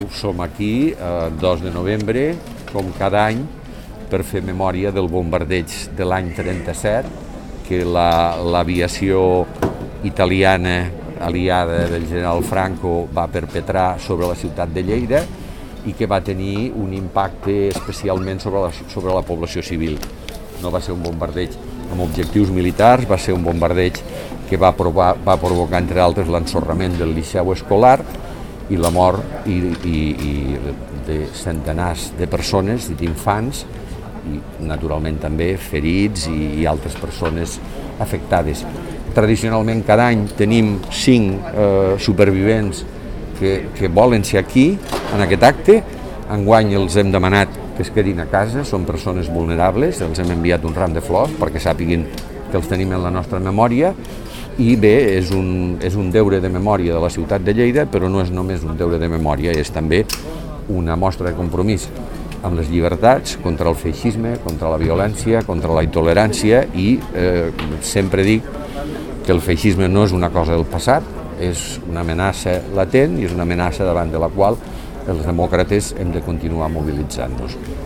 tall-de-veu-de-lalcalde-miquel-pueyo-sobre-lacte-en-record-del-bombardeig-de-1937-al-liceu-escolar